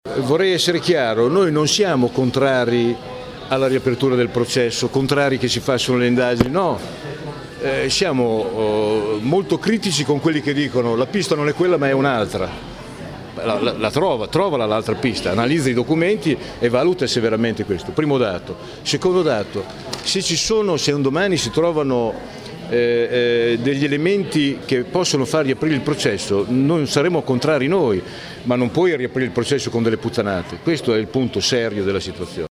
Le parole di Bolognesi – pronunciate a margine di un incontro col segretario del Prc Paolo Ferrero davanti alla lapide commemorativa nella sala d’aspetto che la bomba distrusse – rendono incandescente il clima a pochi giorni dal 29° anniversario, animato dal dibattito sulla necessità di ripensare le commemorazioni in vista del trentennale della strage, nel 2010.
Ascolta Paolo Bolognesi: